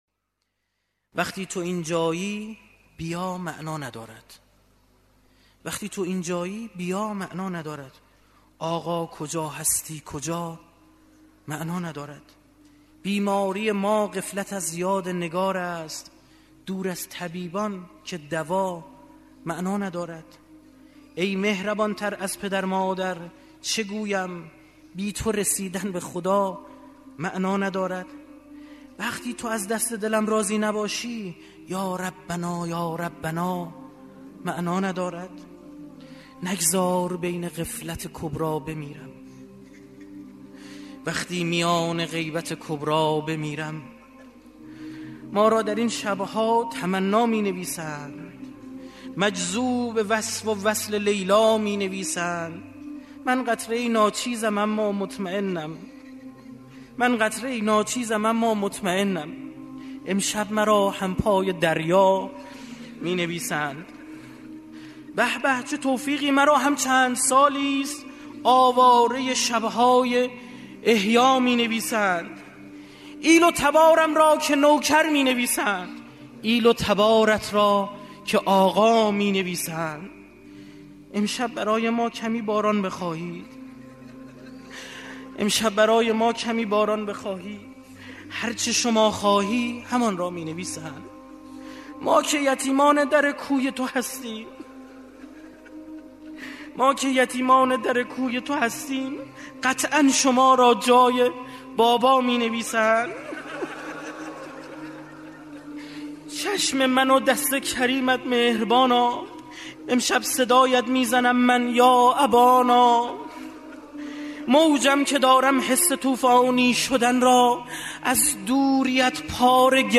شهر: تهران
شعر خوانی